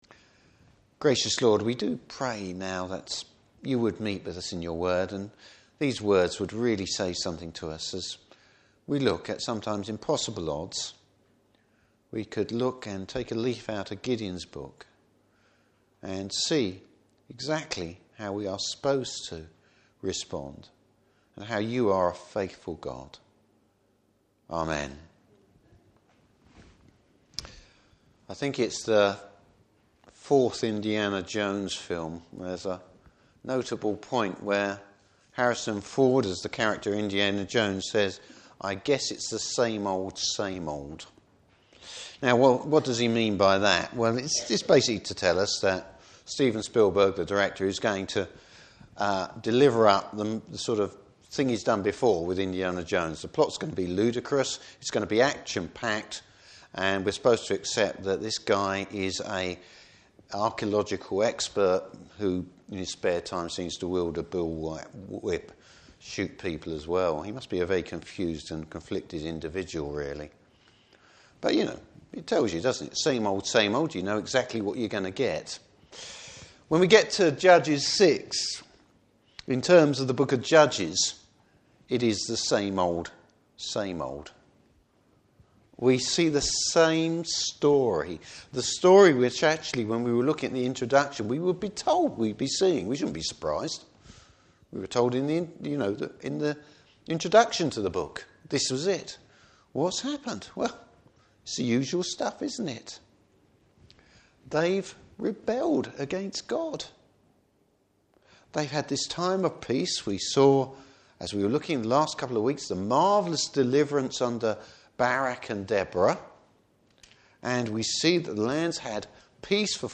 Service Type: Evening Service The Lord sees Gideon’s potential even if Gideon can’t!